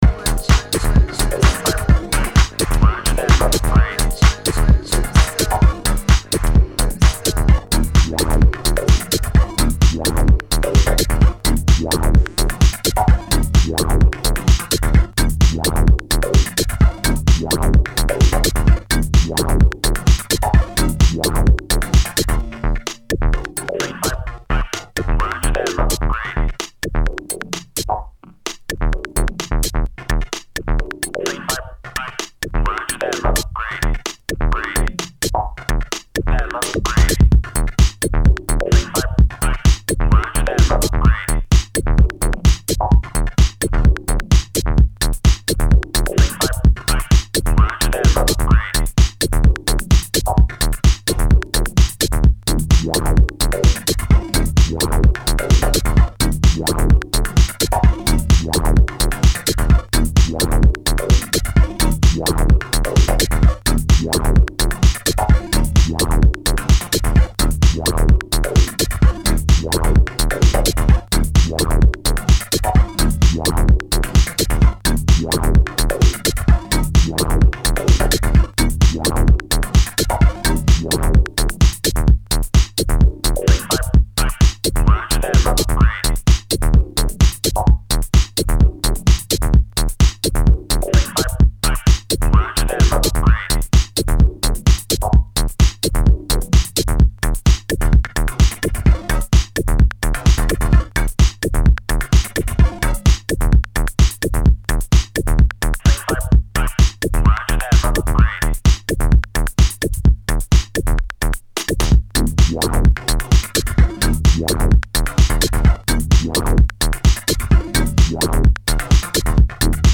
Muzyka/Music: House/Disco/Minimal Techno/Funk
Exclusive DJ mix session.